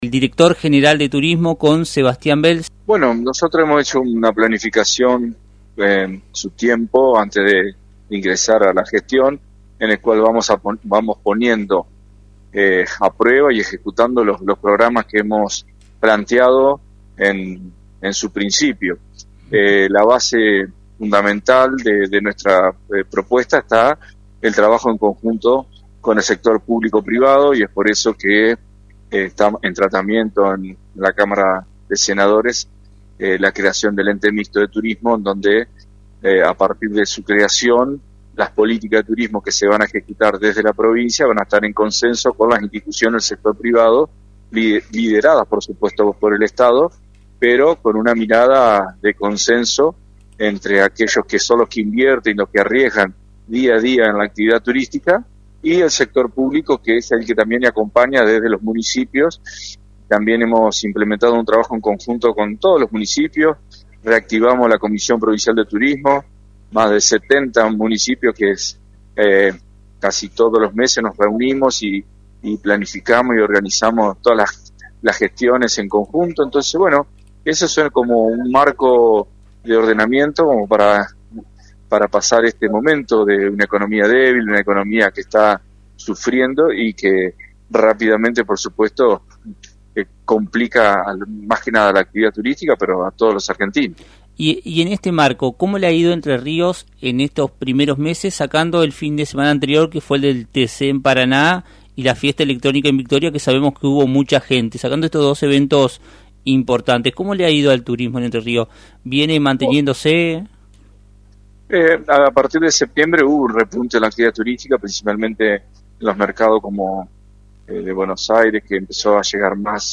Sebastián Bell, Director General de Turismo de Entre Ríos, abordó en Radio Victoria, diversos aspectos sobre el desarrollo y la promoción del turismo en la provincia, los desafíos actuales y las actividades que se vienen para cerrar el año.
En diálogo para el programa “Puntos Comunes” de AM 980, Bell explicó que la estrategia turística de Entre Ríos está basada en la colaboración entre el sector público y privado.